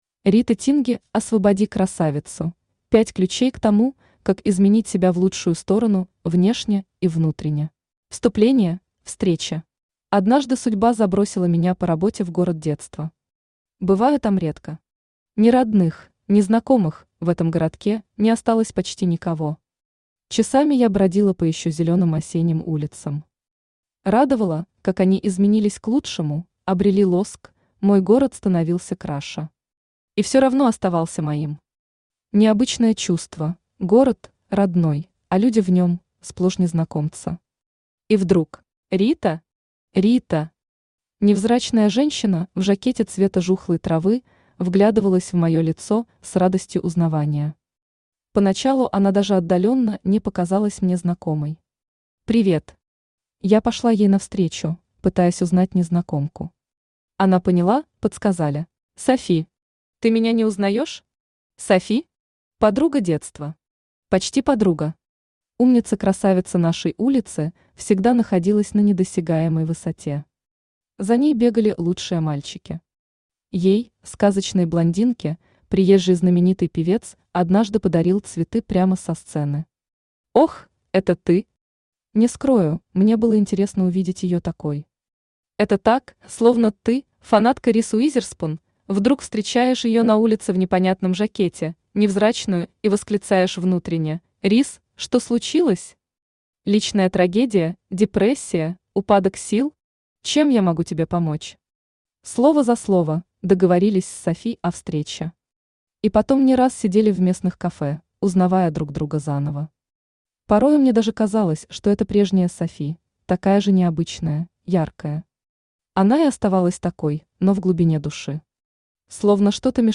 Аудиокнига Освободи красавицу. Пять ключей к тому, как изменить себя в лучшую сторону внешне и внутренне | Библиотека аудиокниг
Пять ключей к тому, как изменить себя в лучшую сторону внешне и внутренне Автор Рита Тинги Читает аудиокнигу Авточтец ЛитРес.